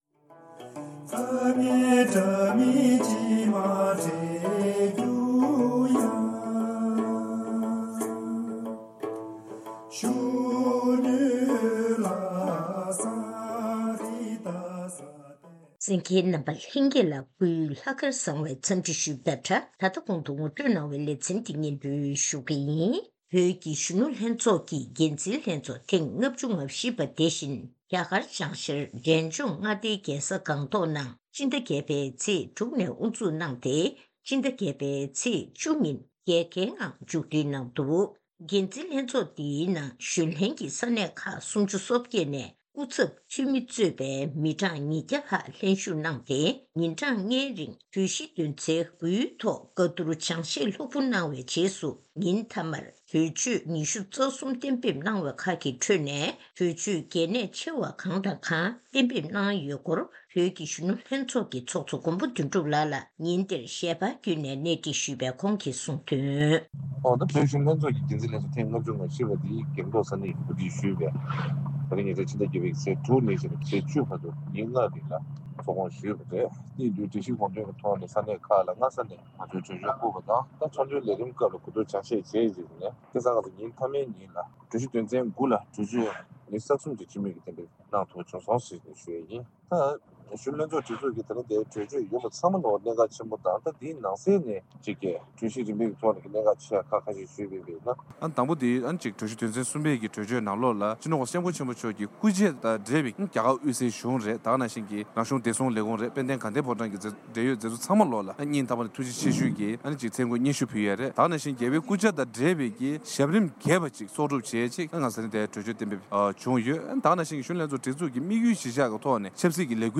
གཞོན་ནུ་ལྷན་ཚོགས་ཀྱི་འགན་འཛིན་ལྷན་ཚོཊ་ཐེངས་ ༥༤ པའི་ཐོག་ཧི་མ་ལ་ཡའི་རི་བརྒྱུད་ཁུལ་གྱི་མངའ་སྡེ་འདིའི་ཆེས་མཐོའི་དཔོན་རིགས་རྣམས་ཀྱིས་བཀའ་སློབ་གསུང་བཤད་གནང་པ།